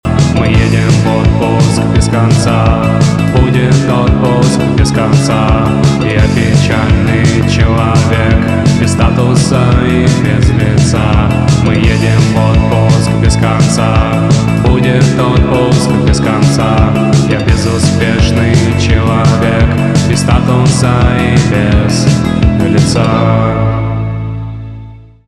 инди
гитара , барабаны
грустные , печальные , депрессивные